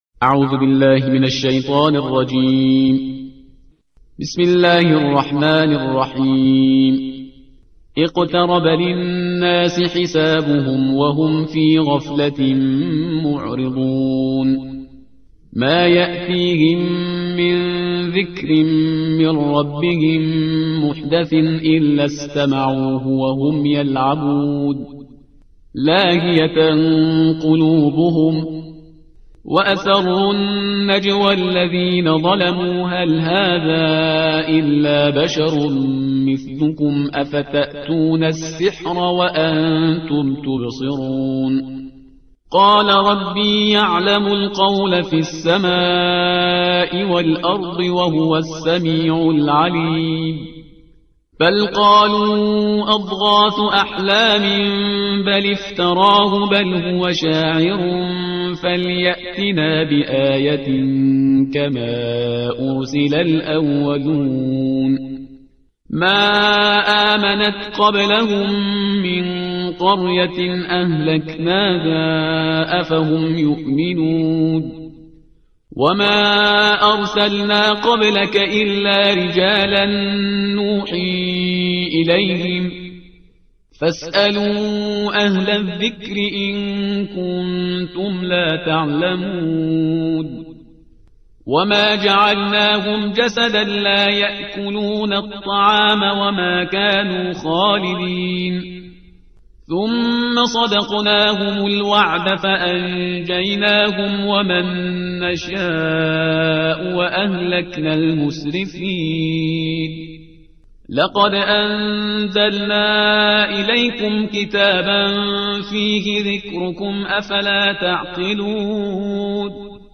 ترتیل صفحه 322 قرآن